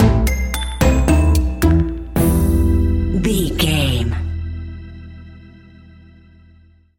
Aeolian/Minor
ominous
eerie
piano
drums
synthesizer
spooky